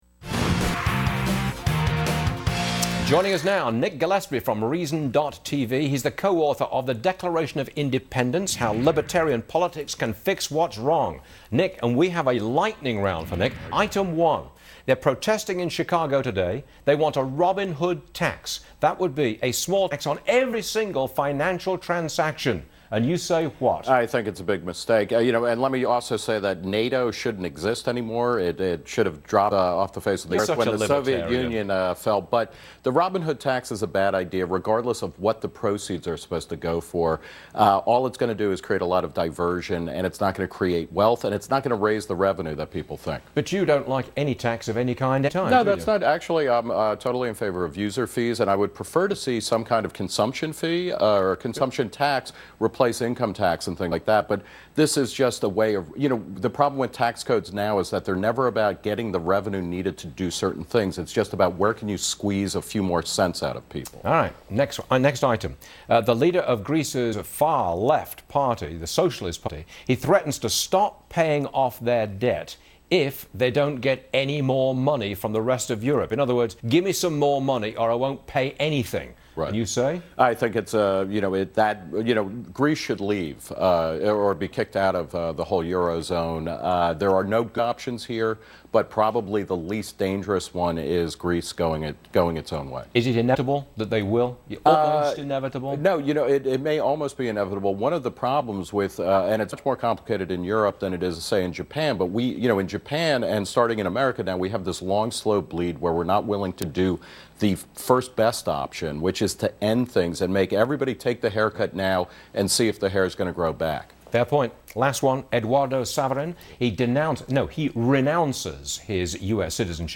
Reason's Nick Gillespie appeared on Fox Business' Varney & Co. to discuss why the Robin Hood tax is a bad idea, why Greece should be kicked out of the Euro Zone, and why Chuck Schumer shouldn't be attacking Eduardo Saverin for renouncing his U.S. citizenship.